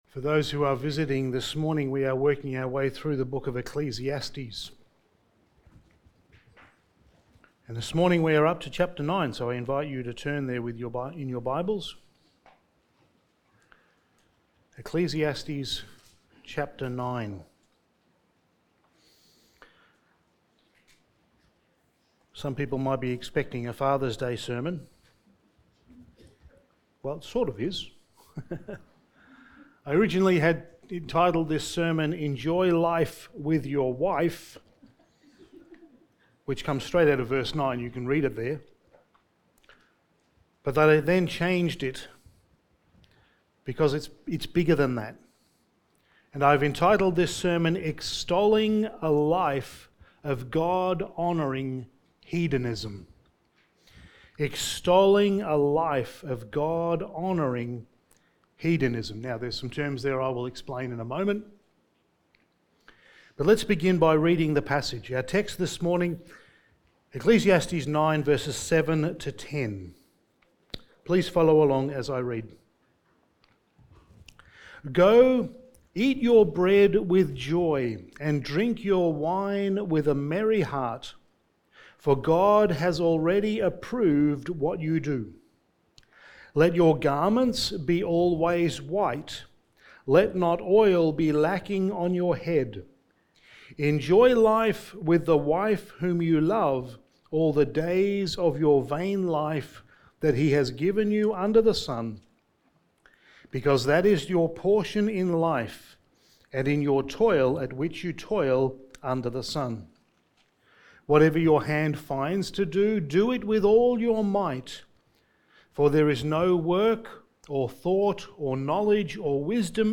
Passage: Ecclesiastes 9:7-10 Service Type: Sunday Morning